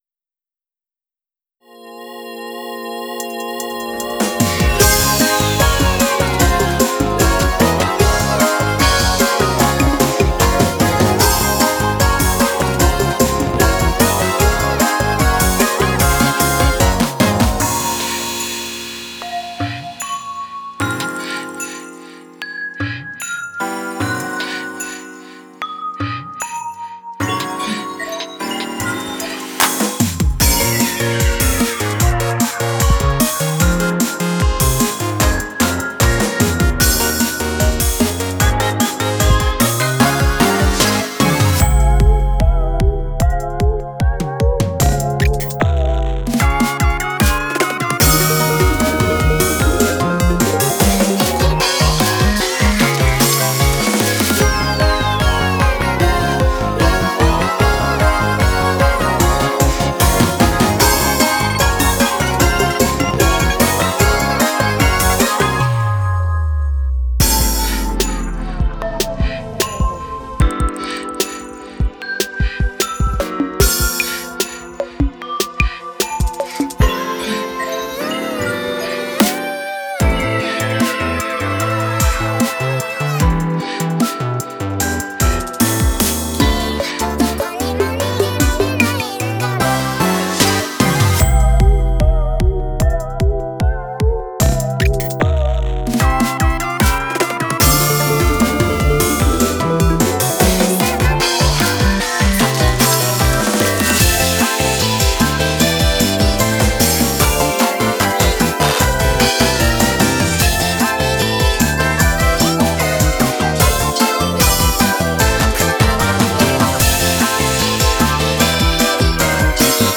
BPMは150です
オケ鑑賞用ハモリ乗せ